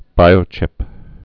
(bīō-chĭp)